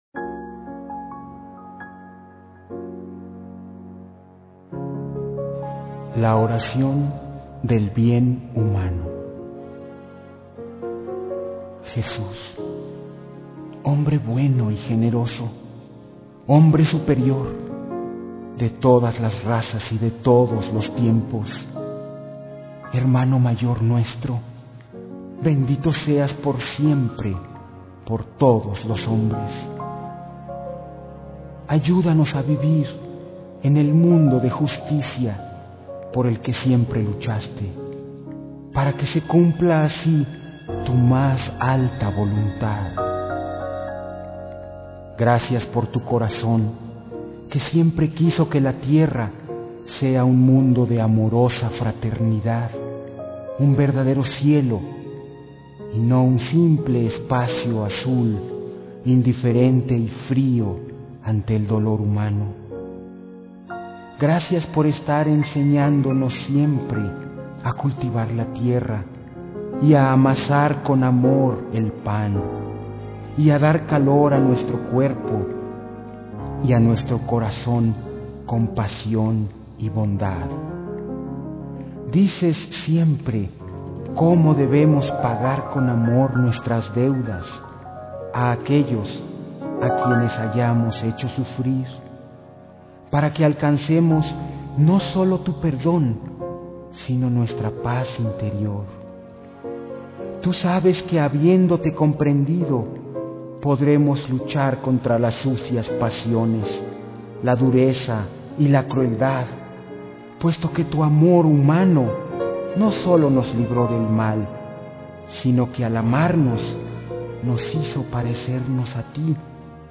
P O E M A S
C A L I D A D     M O N O